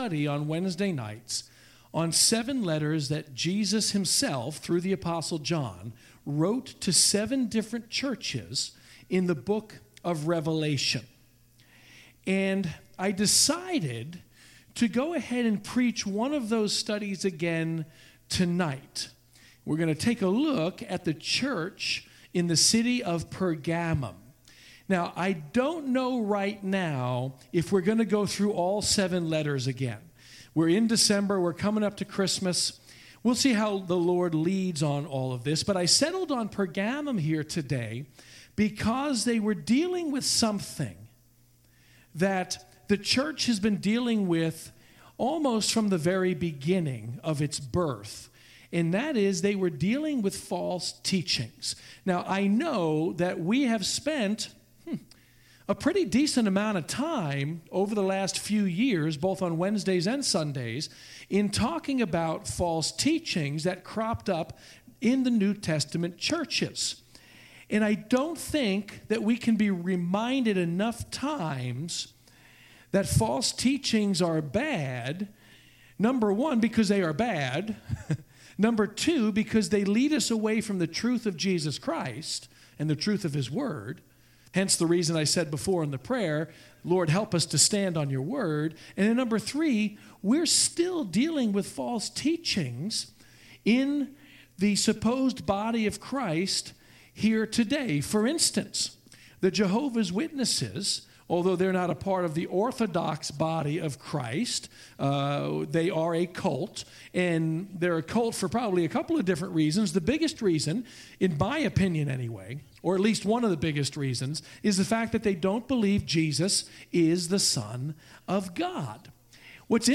Wednesday evening(Bible Study) November 28th 2018